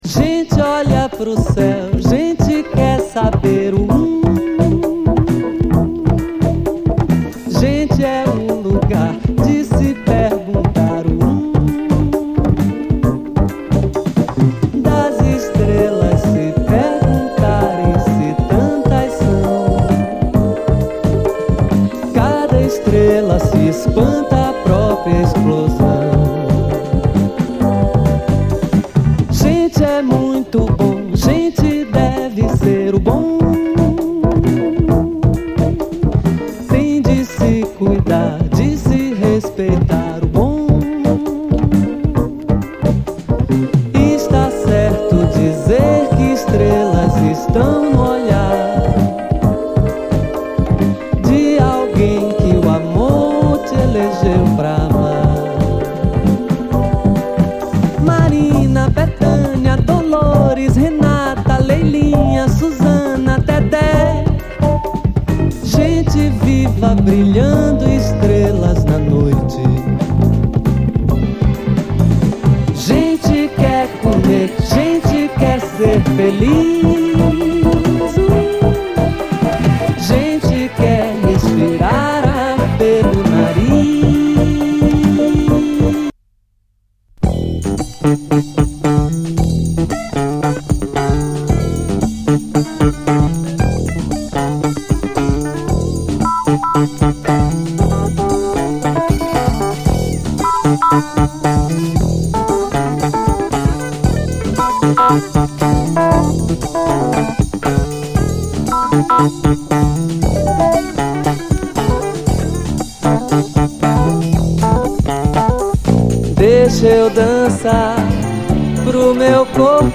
音質もグレイトな正規ライセンス・プレス！